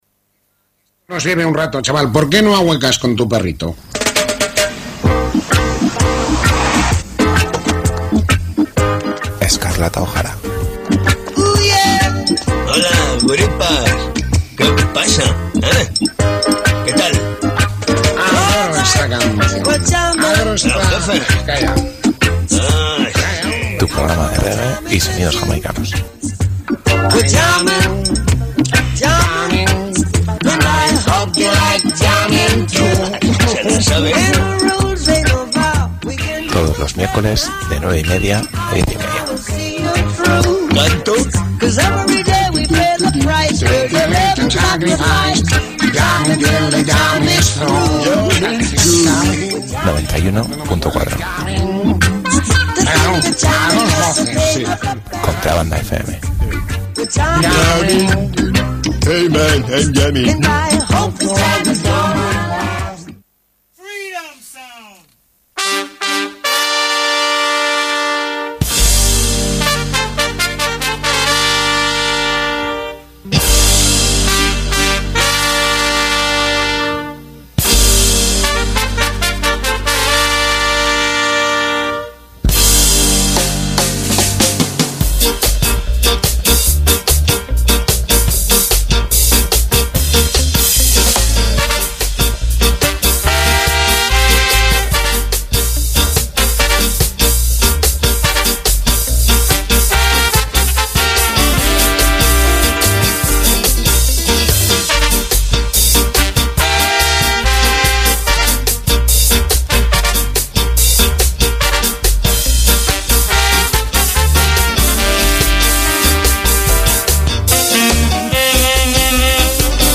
reggae
trio vocal